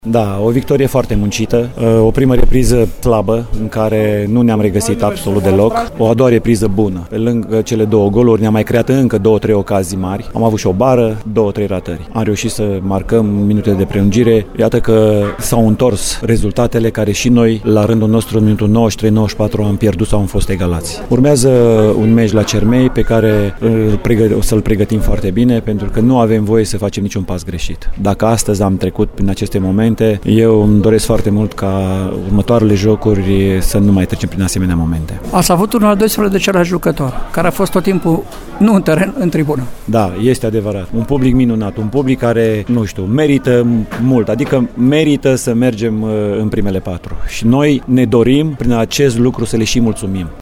Ascultăm reacții culese, după joc